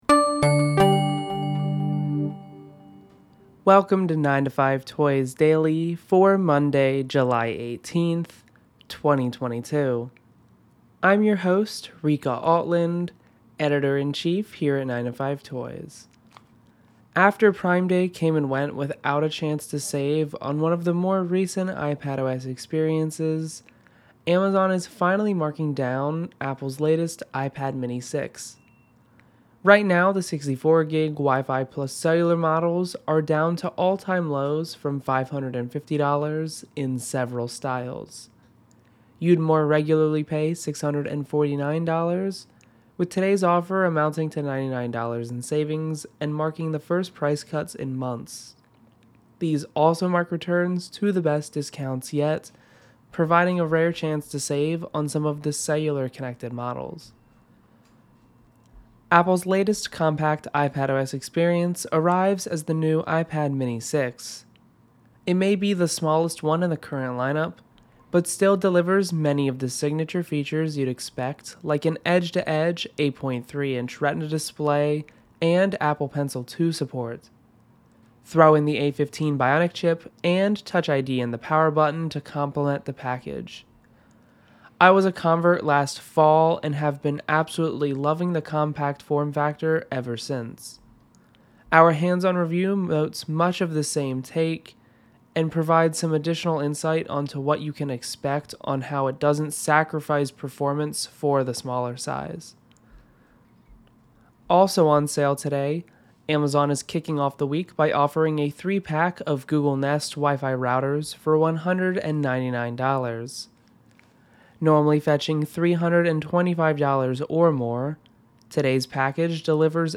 Listen to a recap of the best deals and news from 9to5Toys each day at noon. 9to5Toys Daily is available on iTunes and Apple PodcastsGoogle Play, or through our dedicated RSS feed.